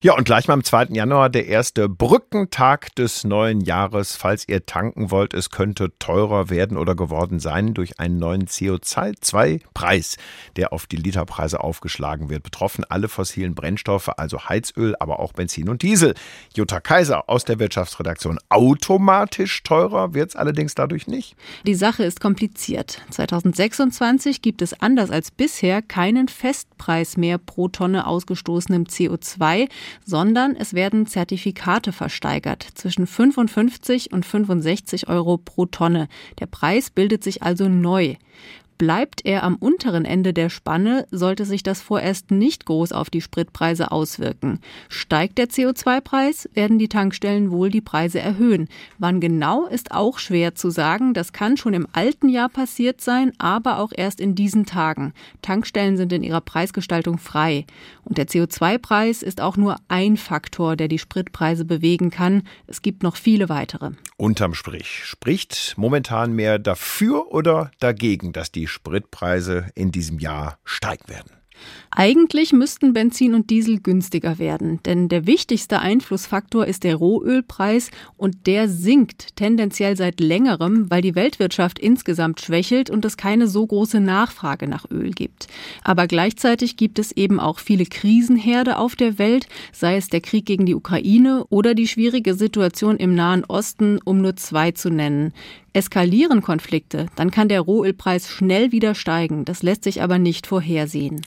Moderator/in
Interview mit